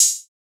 TS OpenHat_1.wav